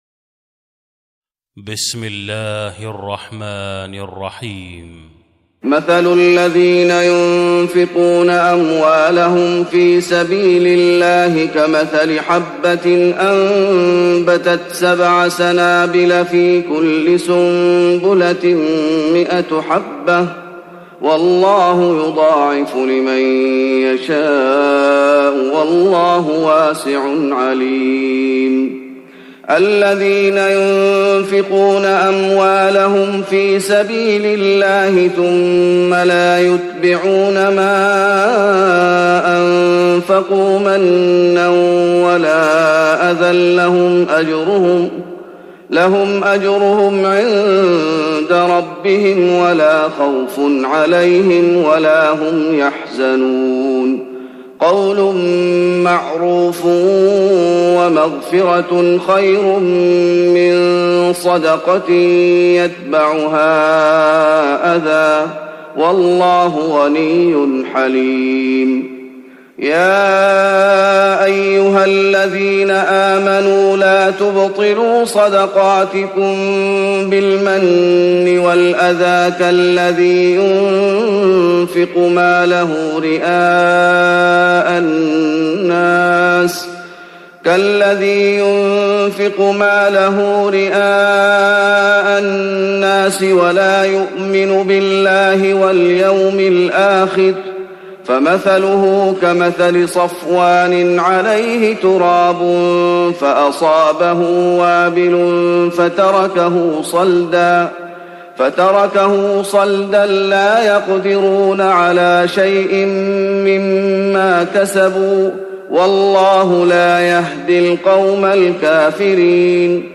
تهجد رمضان 1416هـ من سورة البقرة (261-286) Tahajjud Ramadan 1416 H from Surah Al-Baqara > تراويح الشيخ محمد أيوب بالنبوي 1416 🕌 > التراويح - تلاوات الحرمين